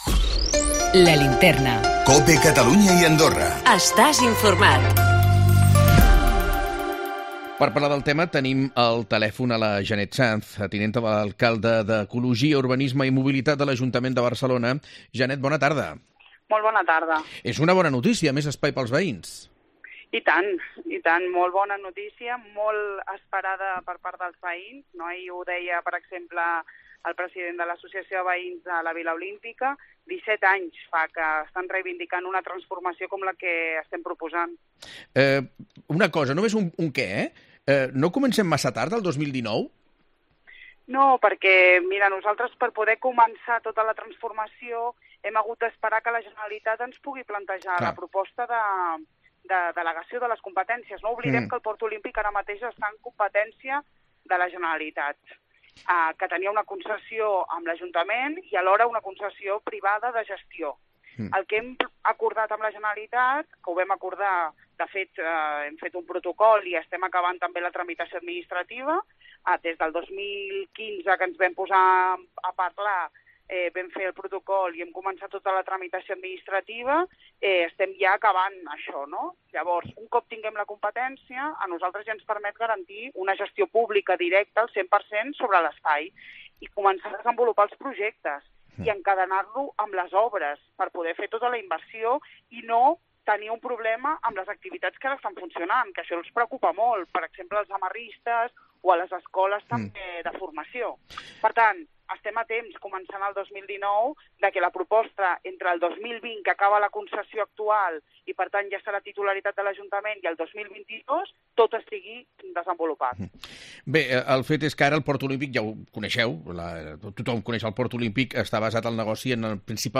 Barcelona traurà l'oci nocturn del port Olímpic i destinarà més espai als veïns. Ens ho explica Janet Sanz, tinenta d'alcalde d'ecologia, urbanisme i mobilitat.